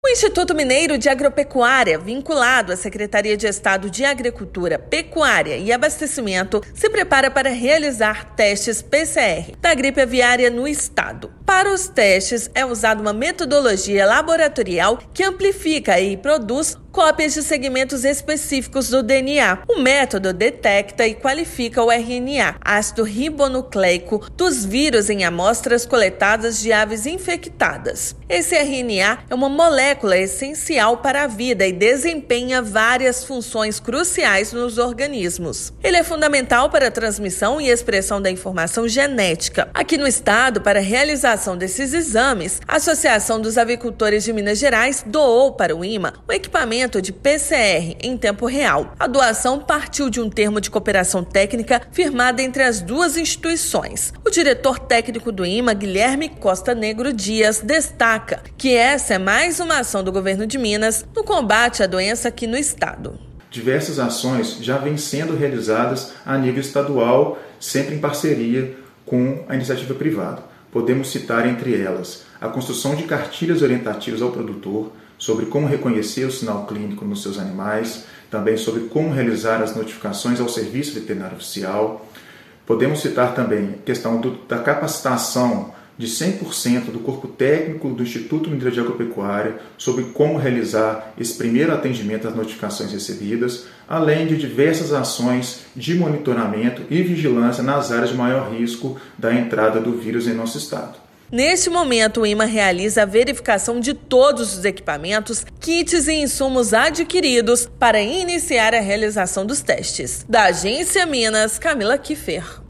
Equipamento para os testes foi doado ao Instituto Mineiro de Agropecuária pela Associação dos Avicultores de Minas Gerais (Avimig). Ouça matéria de rádio.